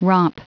Prononciation du mot romp en anglais (fichier audio)
Prononciation du mot : romp